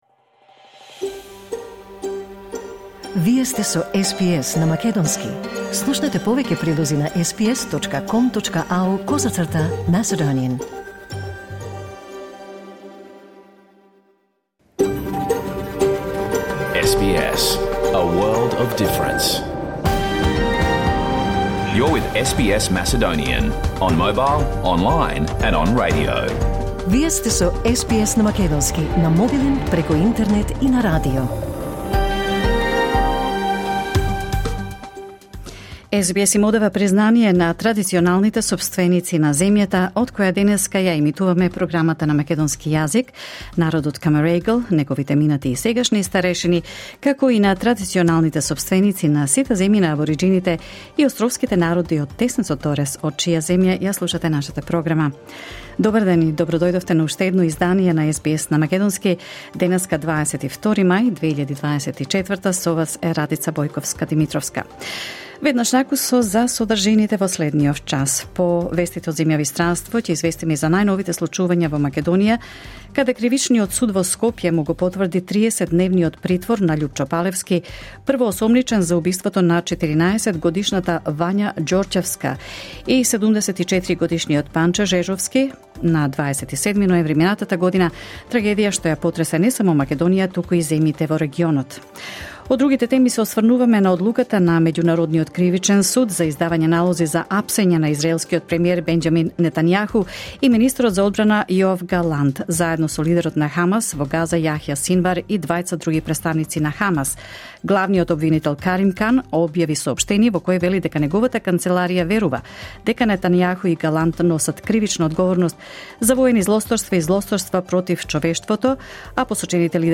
SBS Macedonian Program Live on Air 22 May 2024